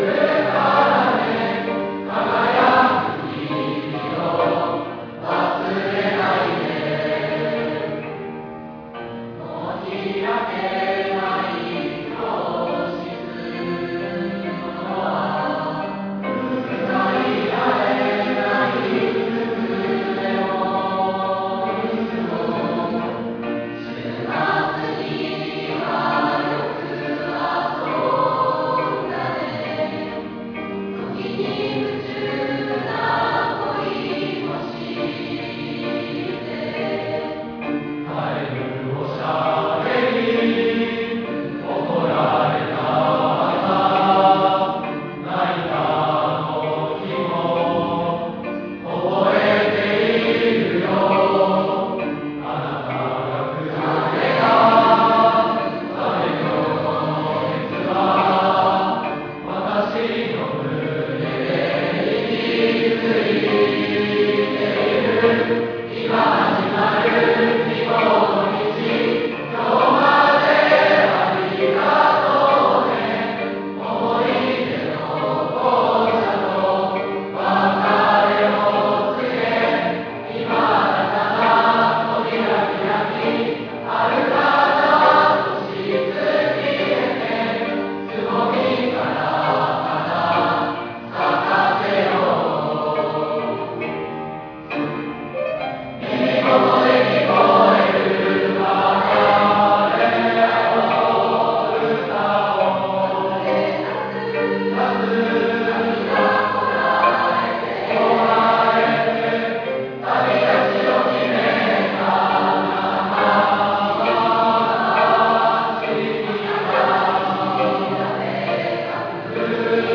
R70313 卒業の歌（予行にて 途中からです）